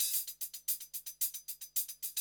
HIHAT LOP8.wav